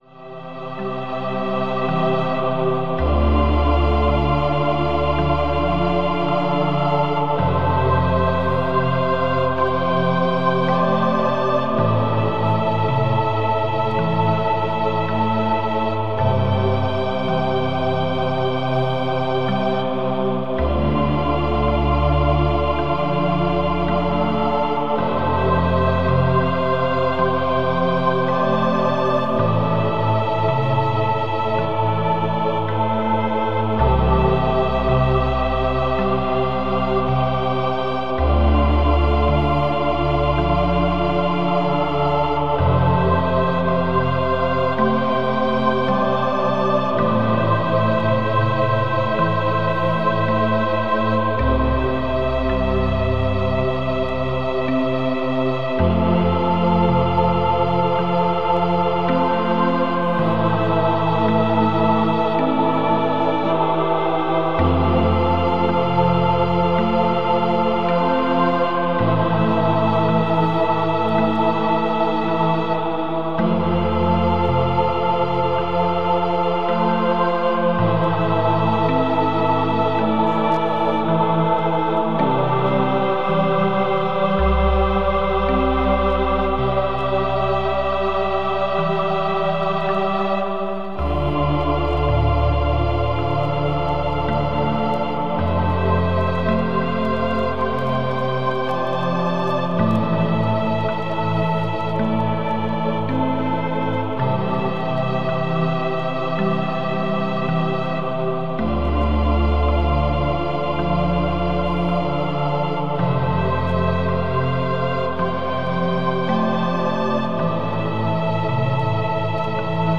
Das Lifeness Harmony Selbsthilfeprogramm: Den wahren Lebensweg finden - Kurt Tepperwein - Hörbuch